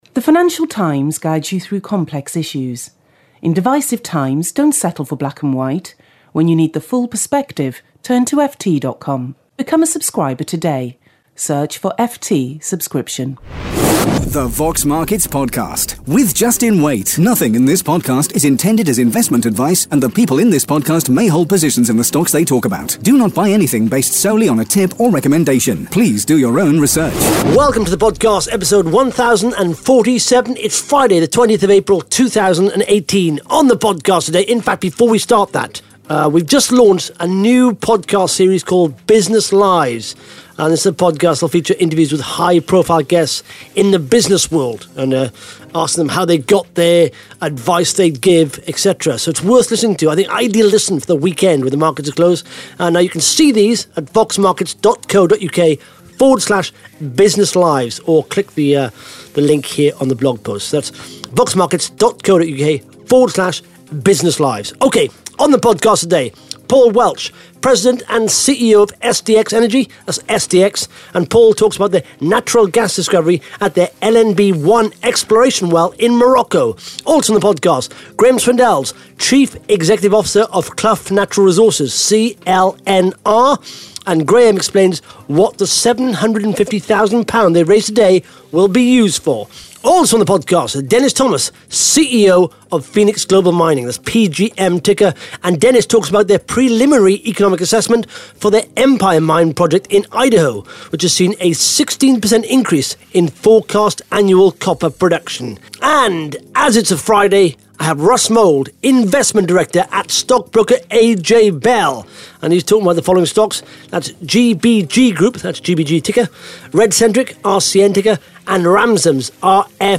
(Interview starts at 2 minute 7 seconds)